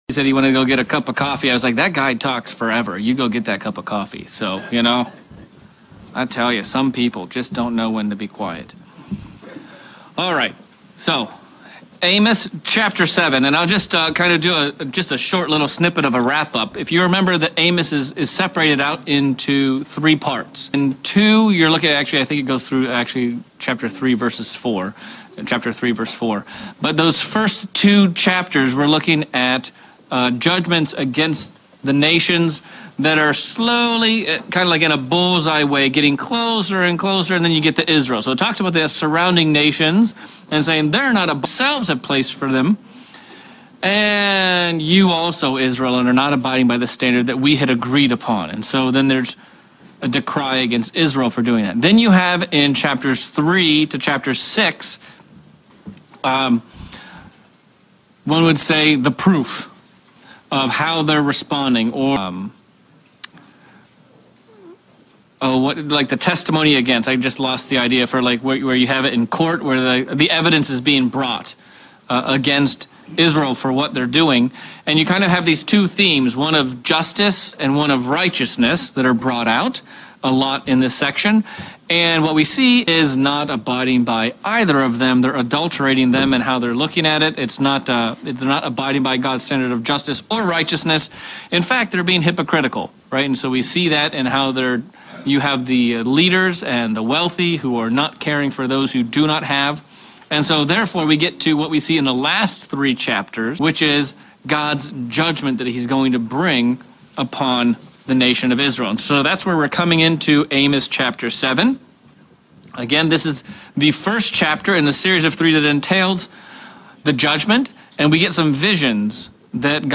March 2024 bible Study-Amos 7
Given in Central Illinois